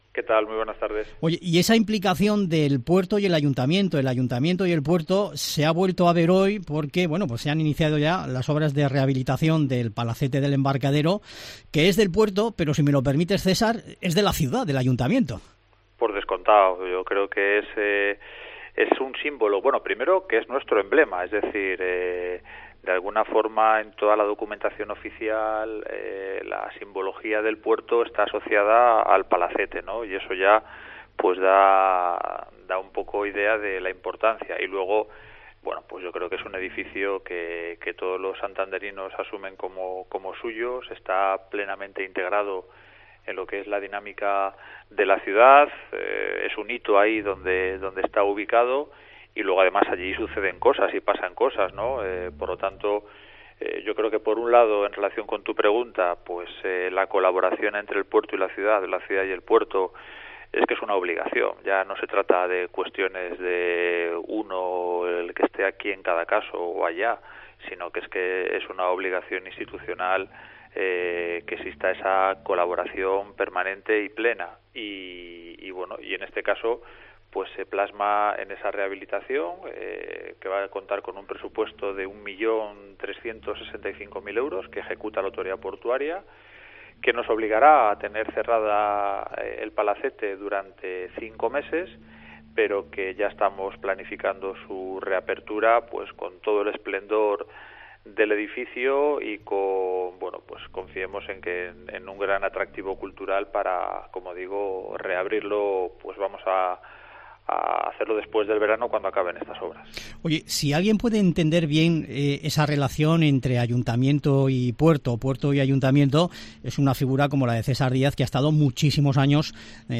La interacción del puerto y la ciudad tiene que estar basada en la lealtad y colaboración institucional, asegura en esta entrevista el Presidente de la Autoridad Portuaria
Cesar Díaz, presidente de la Autoridad Portuaria